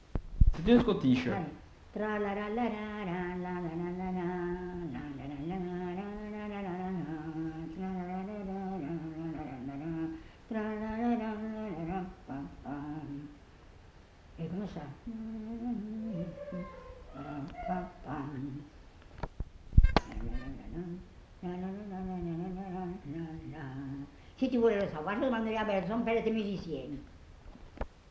Lieu : Mas-Cabardès
Genre : chant
Effectif : 1
Type de voix : voix de femme
Production du son : fredonné
Danse : scottish